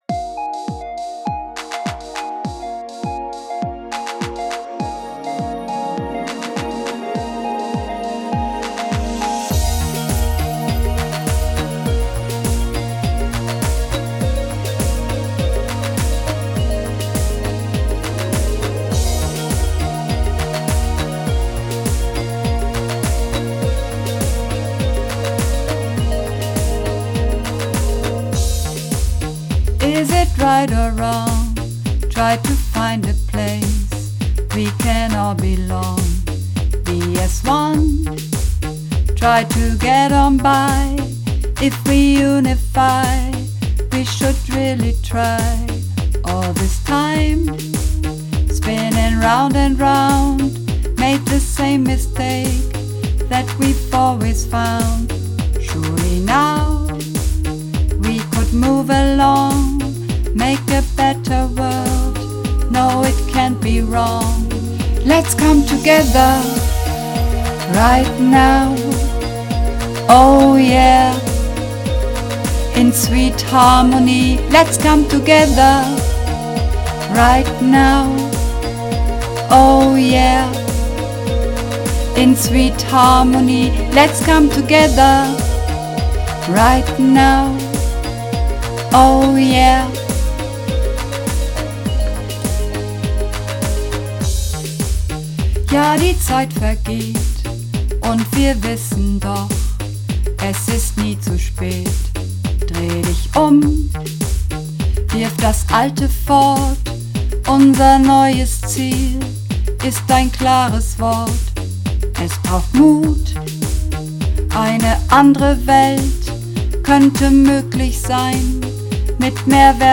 Übungsaufnahmen - Sweet Harmony
Sweet Harmony (Alt)